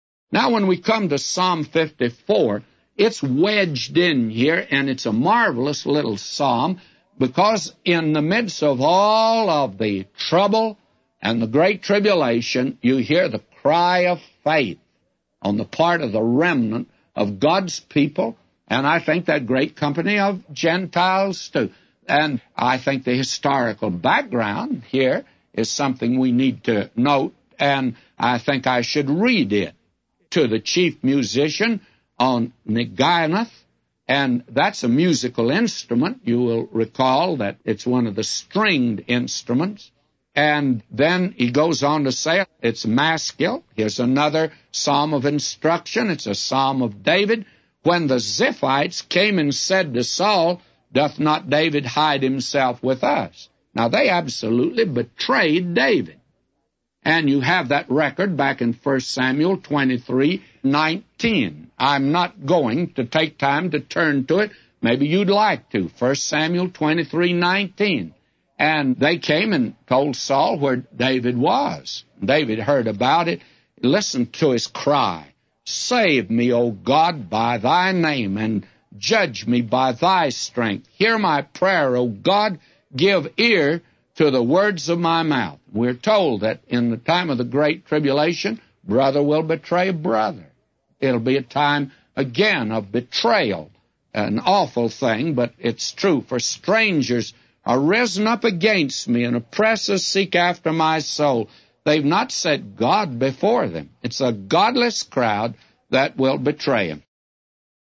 A Commentary By J Vernon MCgee For Psalms 54:1-999